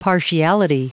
Prononciation du mot partiality en anglais (fichier audio)
Prononciation du mot : partiality